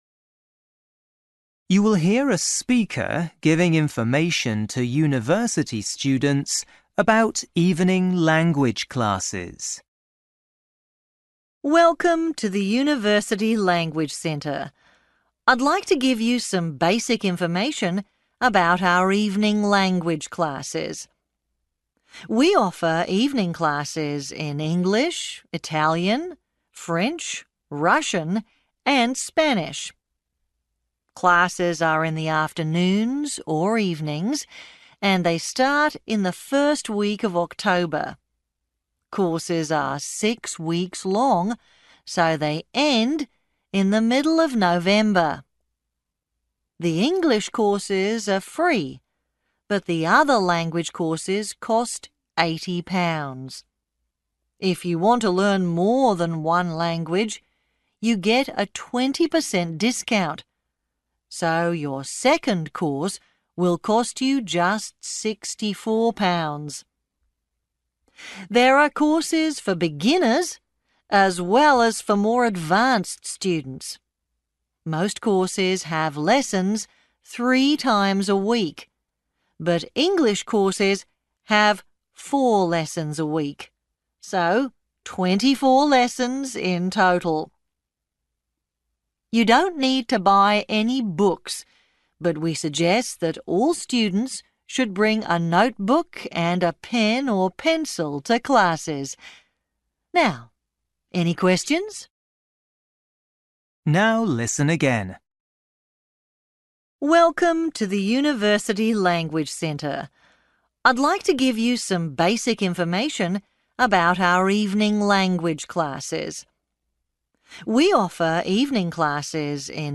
You will hear a speaker giving information to university students about language courses.